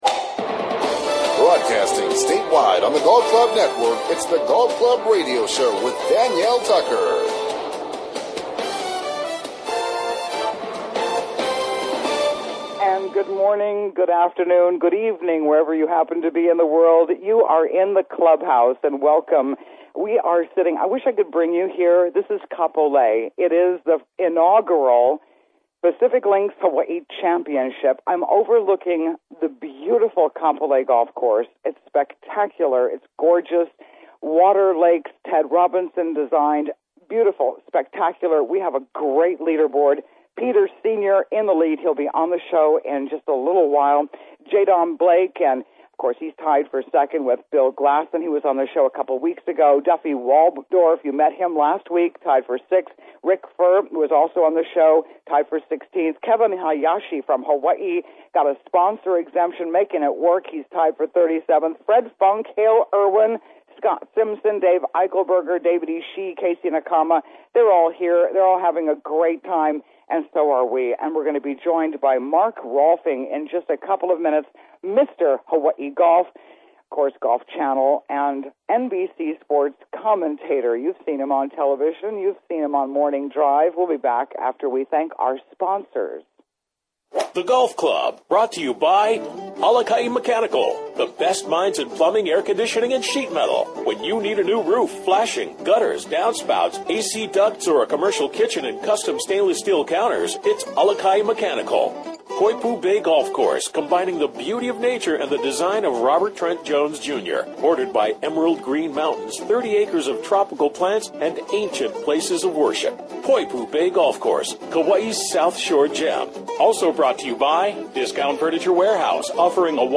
Live from Kapolei GC , Oahu The Pacific Links Championship